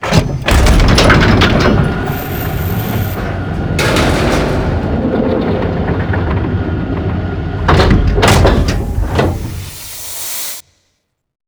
undock.wav